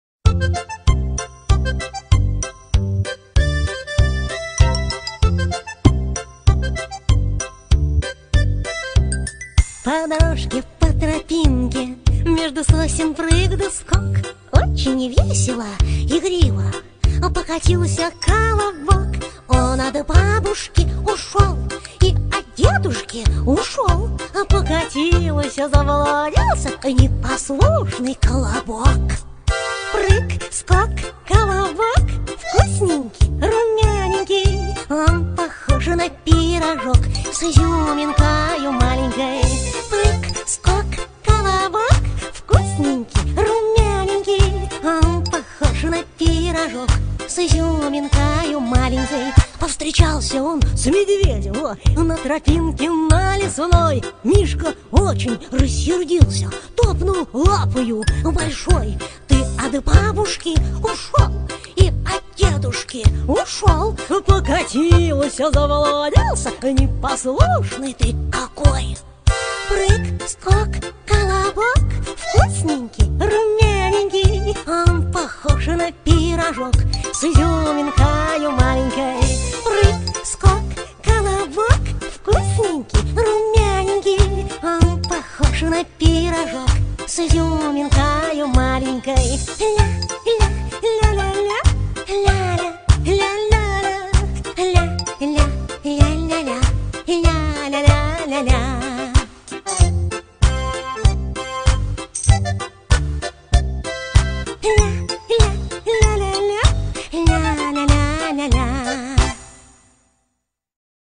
Веселая детская песенка